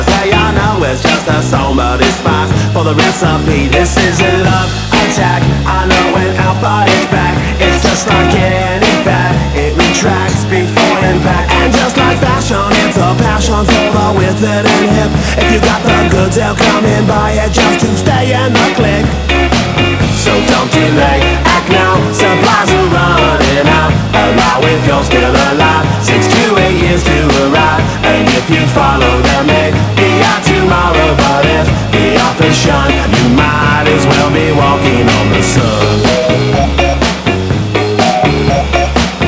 60-ish funky sound